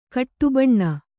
♪ kaṭṭubaṇṇa